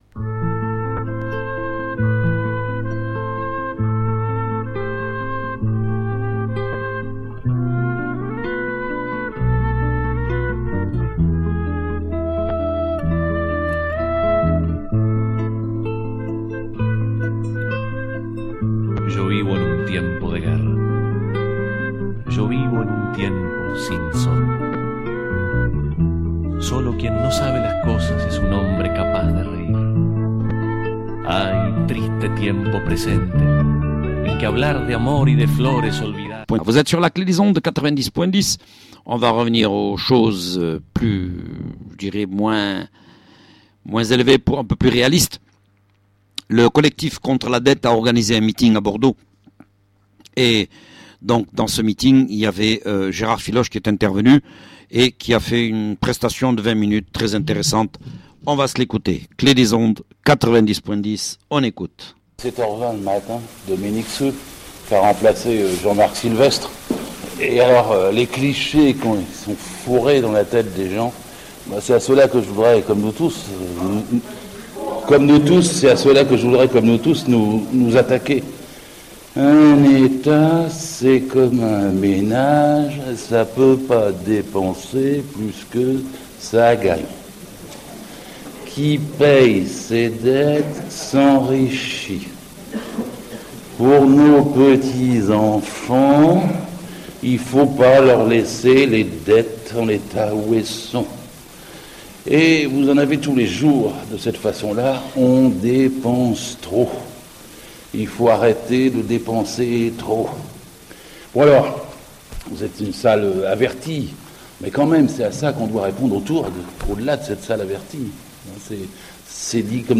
Gérard Filoche, au meeting du Collectif pour un Audit Citoyen de la Dette Publique à Bordeaux